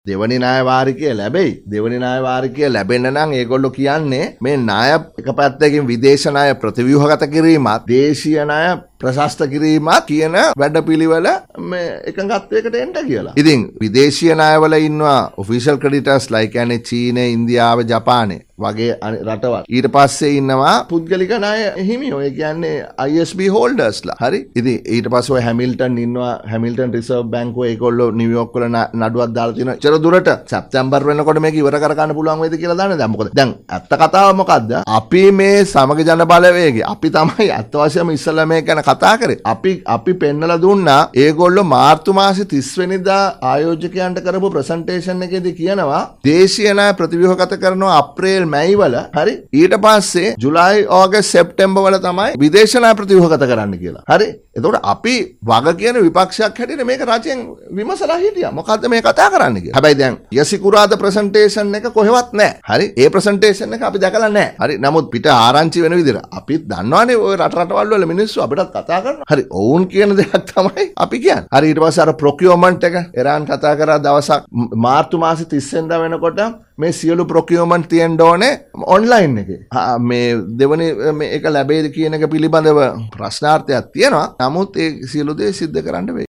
පාර්ලිමේන්තු මන්ත්‍රී හර්ෂද සිල්වා මහතා
ඔහු මෙම අදහස් දැක්වීම සිදු කළේ කොළොඹ පැවති මාධ්‍ය හමුවකට එක්වෙමින්